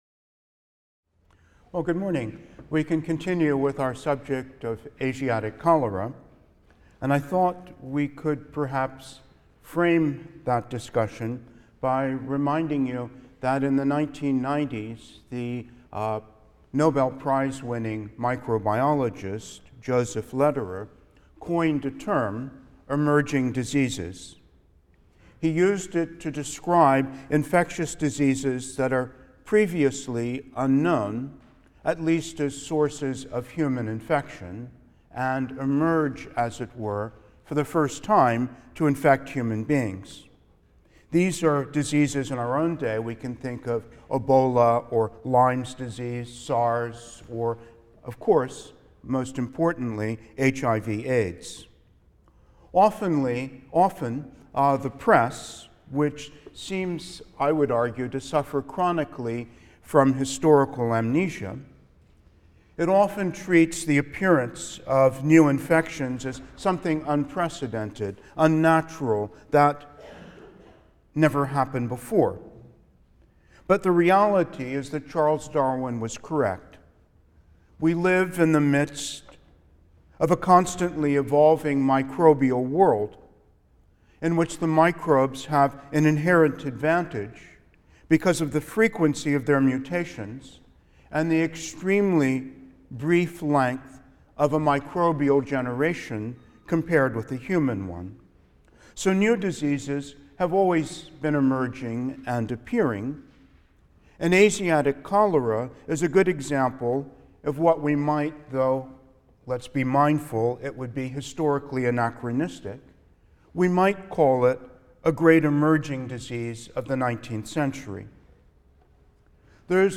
HIST 234 - Lecture 10 - Asiatic Cholera (II): Five Pandemics | Open Yale Courses